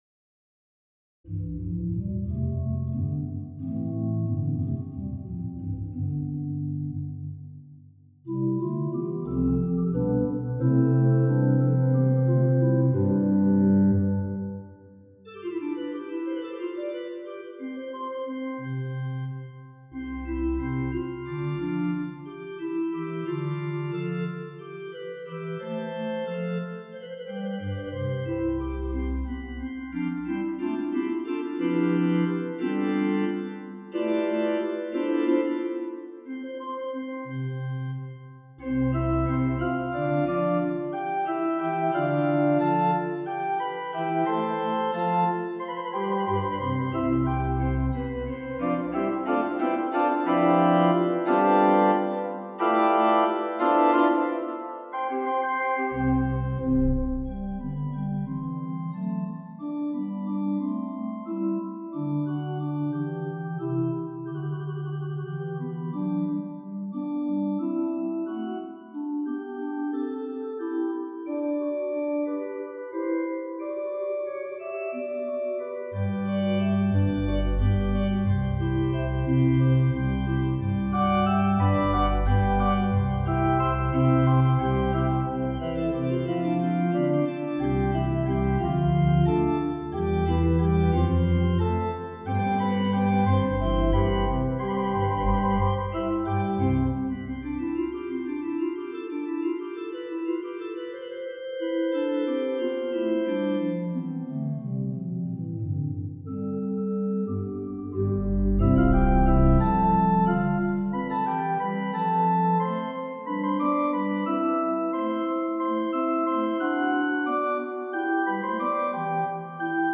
for organ
Dividing a bar into regular irregularities and titling a work in Italian is continued as these small works are fashioned. Here 7/8 is framed as 3+2+2, in an energetic and humorous way